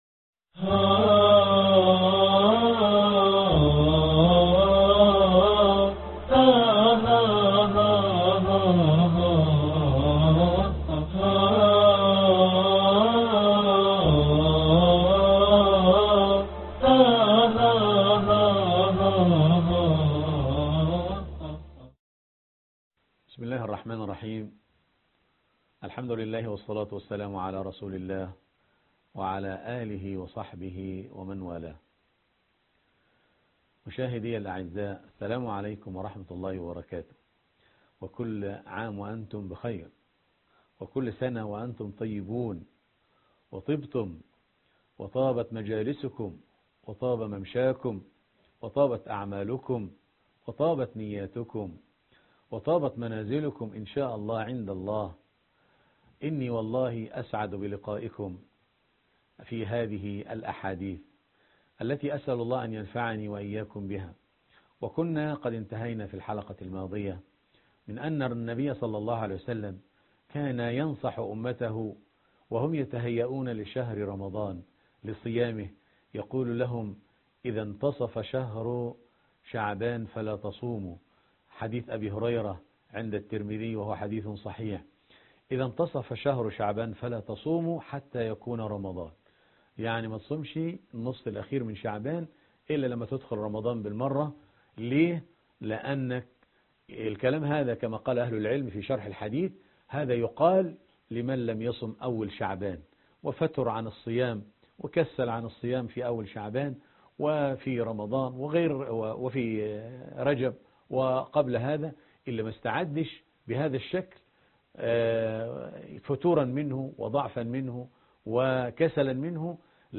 الدرس 8 ( هدي النبي - صلى الله عليه وسلم - في التهيئة لـرمضان ) يا باغي الخير أقبل